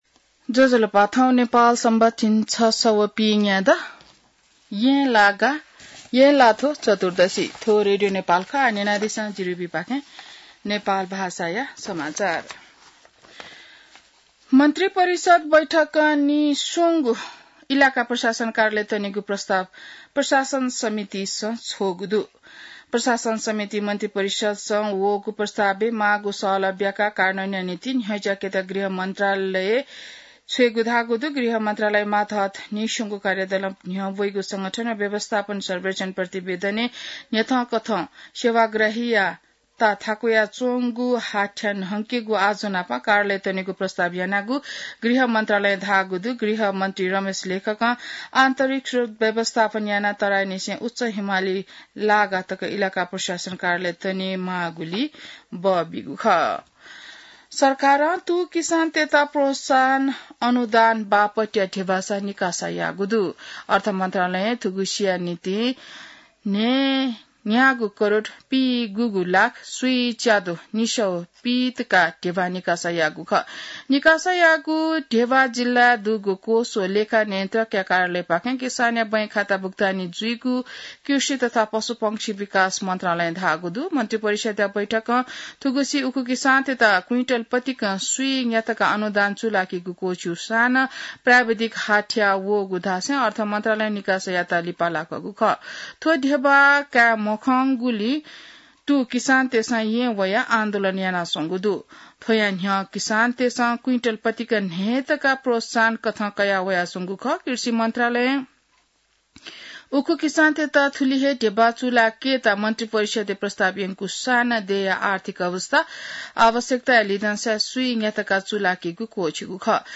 An online outlet of Nepal's national radio broadcaster
नेपाल भाषामा समाचार : ११ भदौ , २०८२